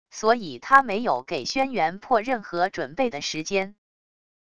所以他没有给轩辕破任何准备的时间wav音频生成系统WAV Audio Player